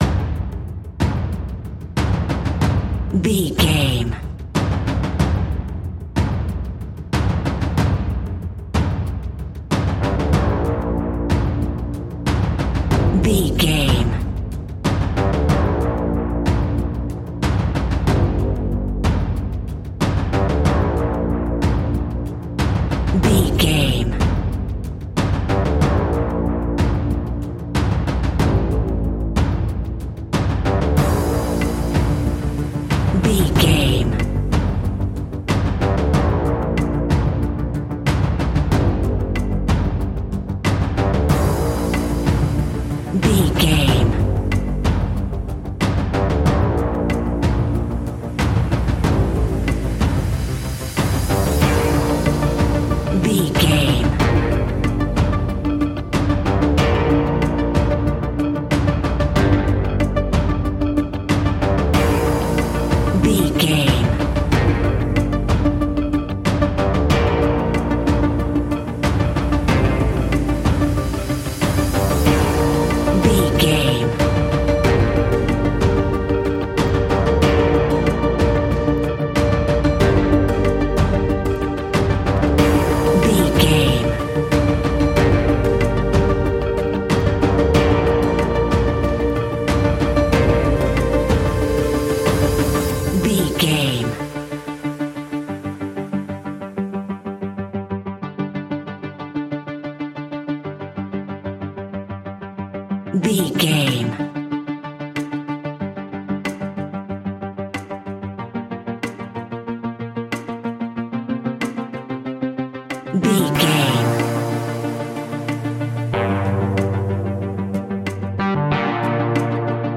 Aeolian/Minor
tension
ominous
dark
haunting
eerie
synthesiser
drums
strings
ticking
electronic music